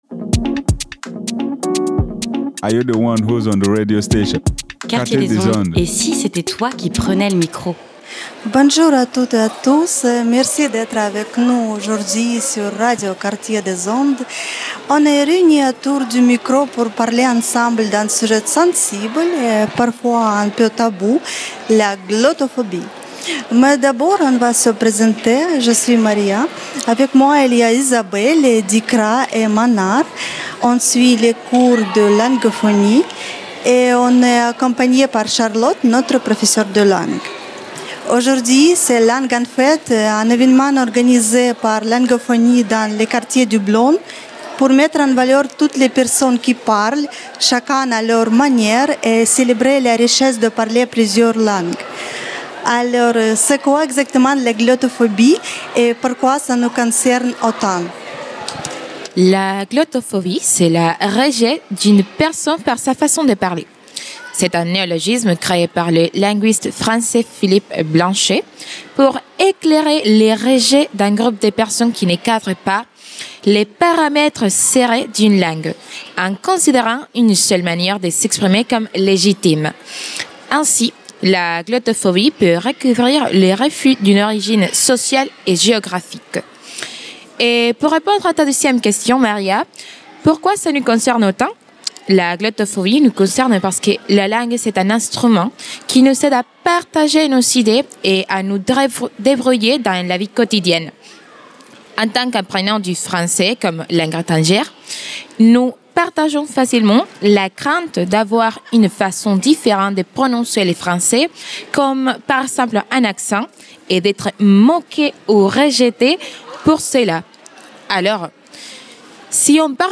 Plateau radio : Discussions et témoignages sur la glottophobie animé avec Langophonies. En direct de l’événement annuel bien connu au Blosne : Langue en fête !